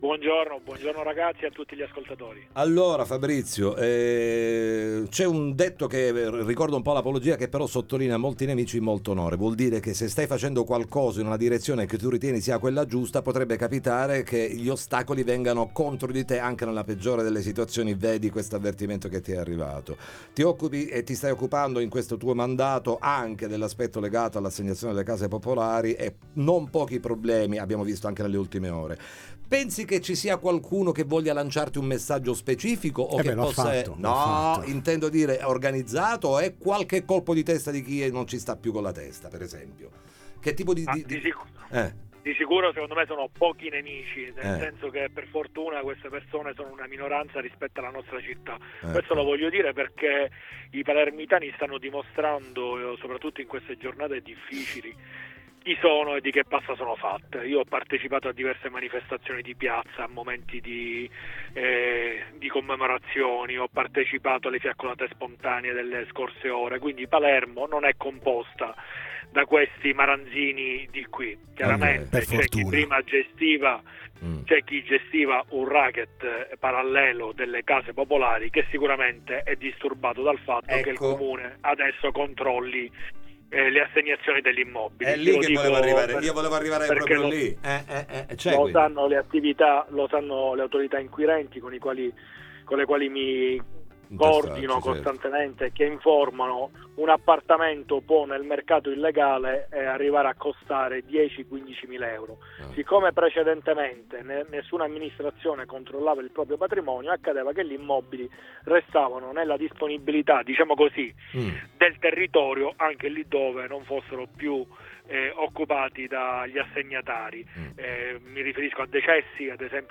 Fiamme e paura nell’alloggio popolare sgomberato a Borgo Nuovo, bomba carta sotto casa dell’assessore Ferrandelli, ne parliamo con lui in collegamento